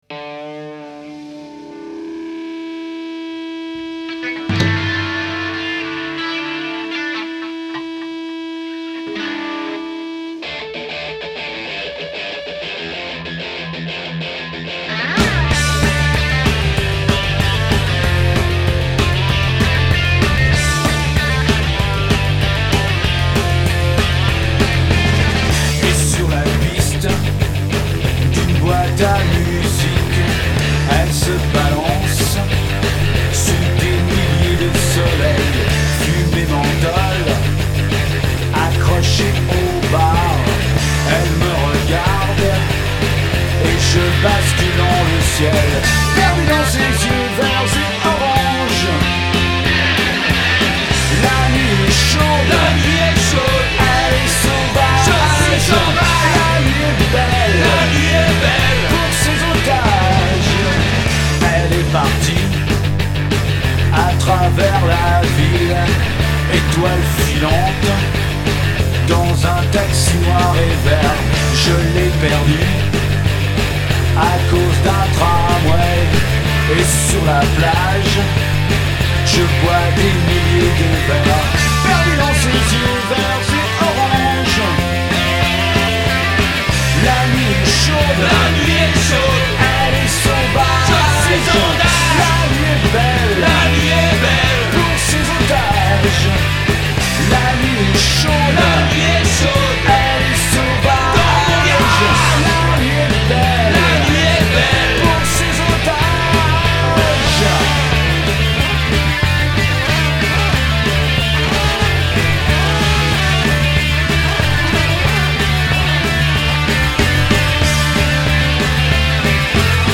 Vespa bass
Drum rules
main voice & guitar right